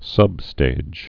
(sŭbstāj)